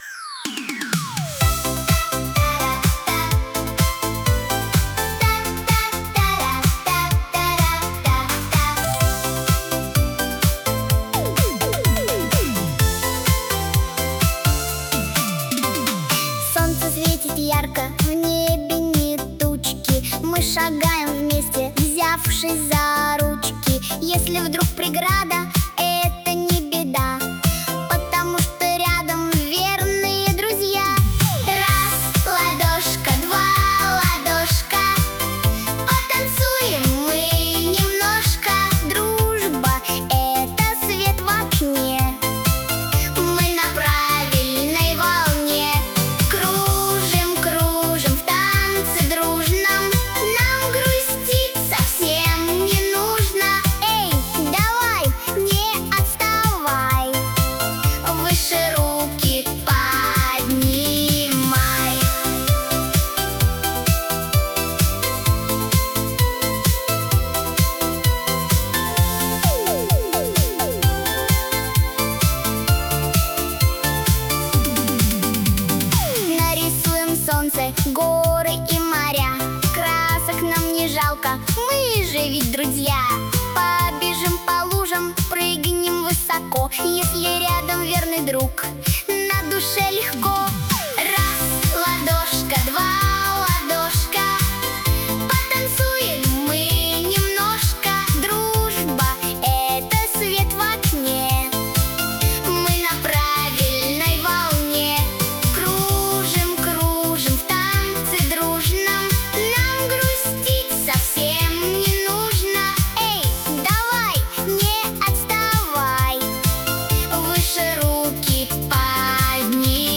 • Качество: Хорошее
• Жанр: Детские песни
танцевальная, дискотека в детском саду